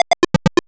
retro_synth_beeps_groove_03.wav